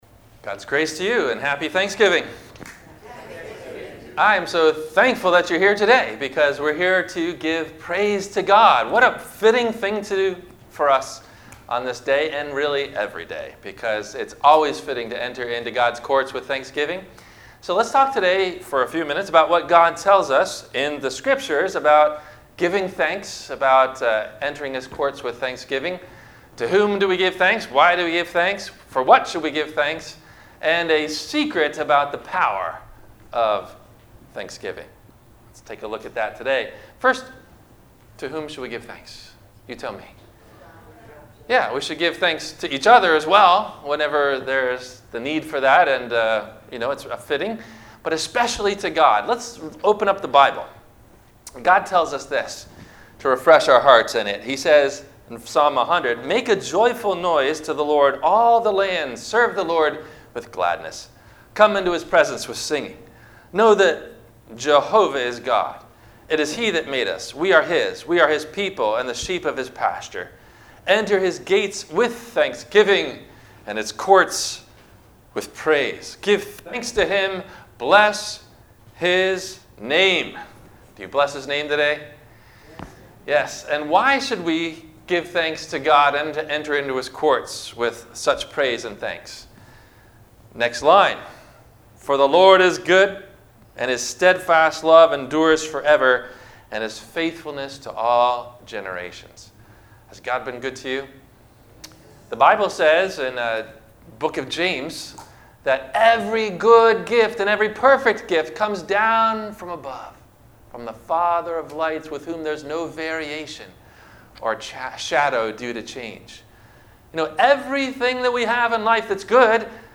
The Power of Thanks - Thanksgiving Day - Thursday Morning - Sermon - November 28 2019 - Christ Lutheran Cape Canaveral